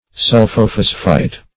Search Result for " sulphophosphite" : The Collaborative International Dictionary of English v.0.48: Sulphophosphite \Sul`pho*phos"phite\, n. (Chem.) A salt of sulphophosphorous acid.